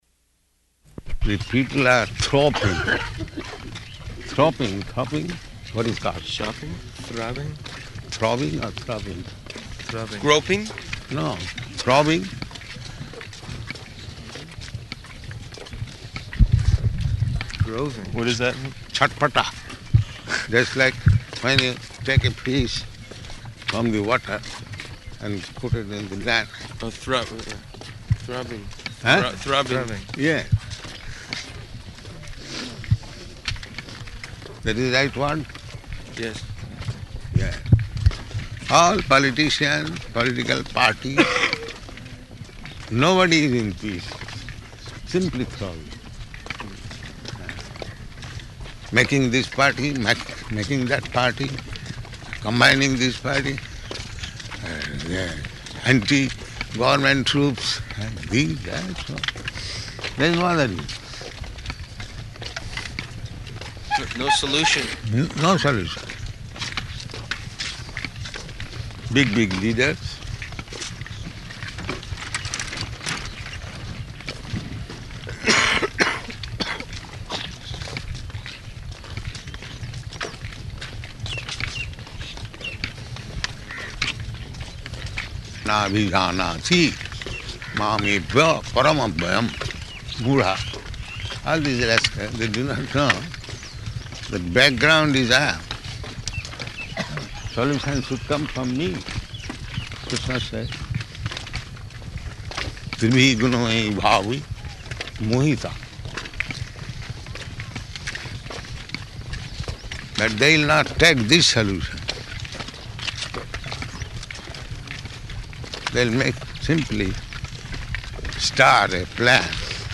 Morning Walk --:-- --:-- Type: Walk Dated: April 1st 1975 Location: Māyāpur Audio file: 750401MW.MAY.mp3 Prabhupāda: The people are thropping.